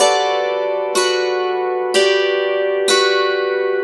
Dulcimer07_125_G.wav